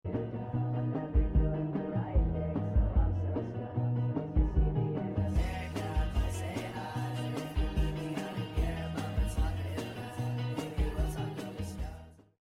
nightcore